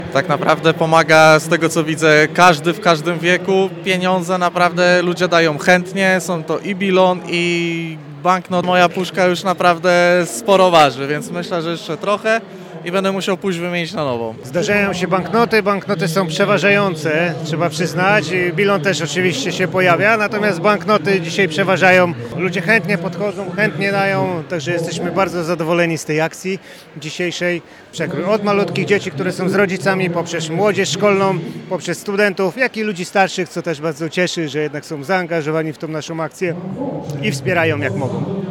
SZCZ-Wolontariusze-WOSP24.mp3